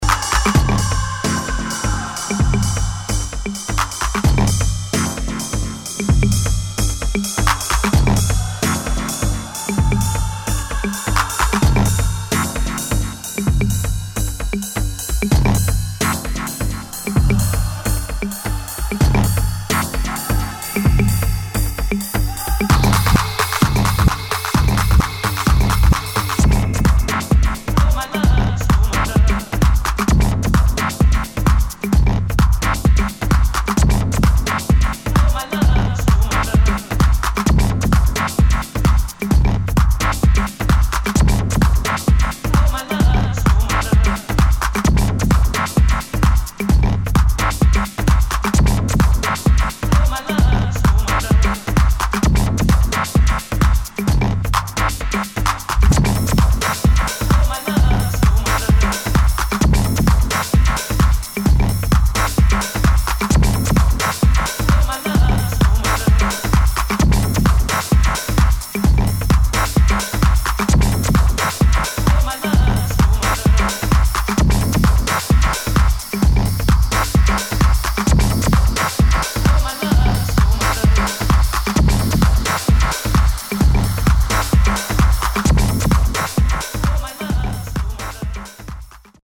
[ TECHNO / MINIMAL ]